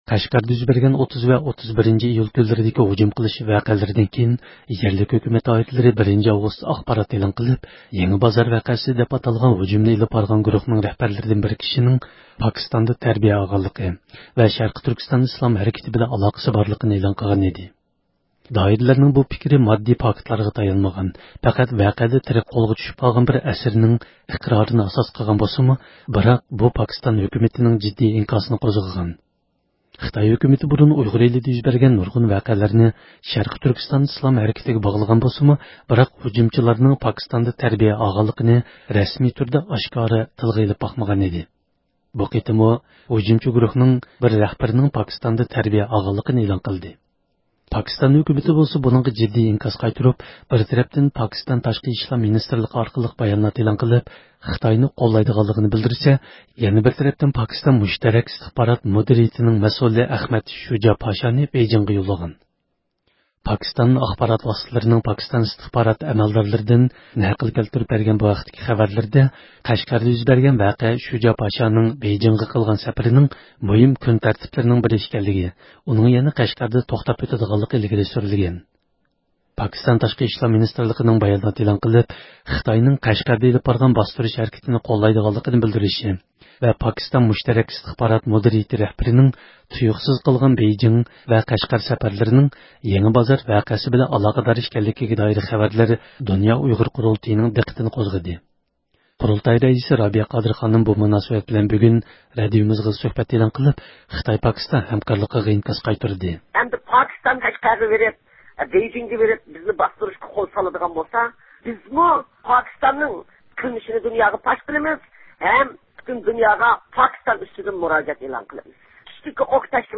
قۇرۇلتاي رەئىسى رابىيە قادىر خانىم بۇ مۇناسىۋەت بىلەن بۈگۈن رادىئومىزغا سۆھبەت ئېلان قىلىپ، خىتاي-پاكىستان ھەمكارلىقىغا ئىنكاس قايتۇردى.